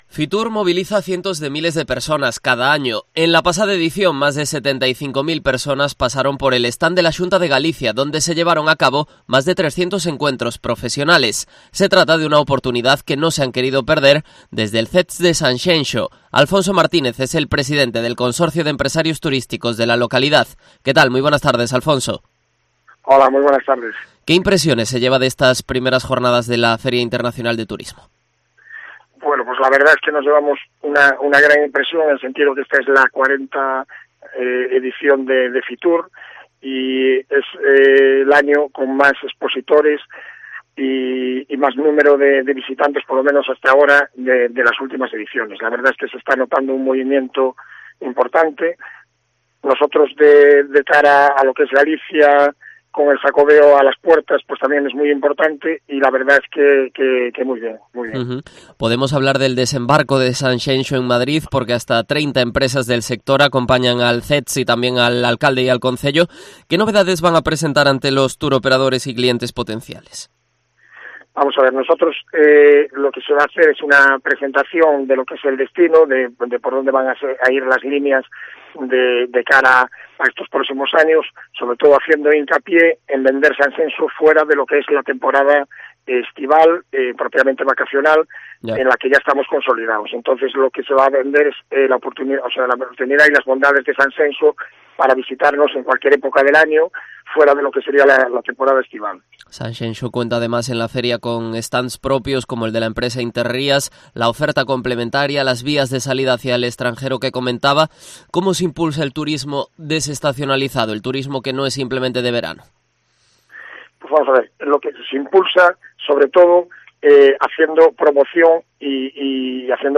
Entrevista
desde Fitur en Herrera en Cope Pontevedra